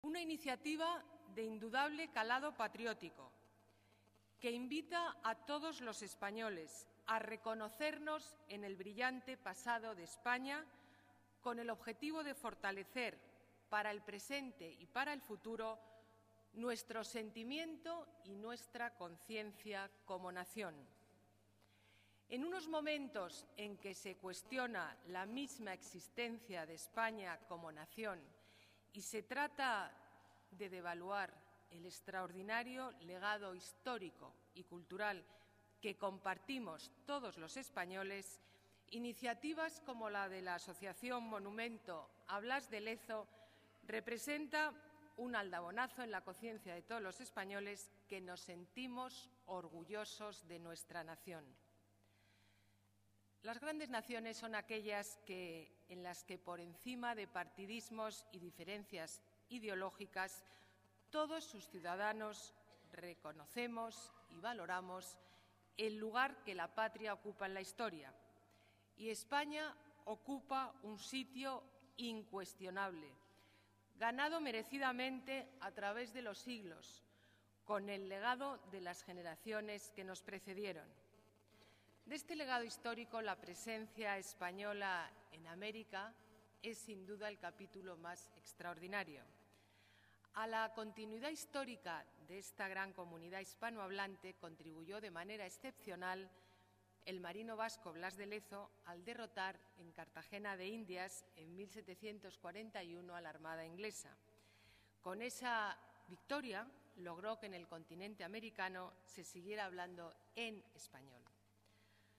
Nueva ventana:sonido alcaldesa